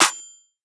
Claps